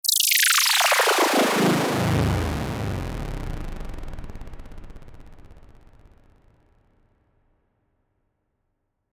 Alien UFO Sound Effect: Unblocked Meme Soundboard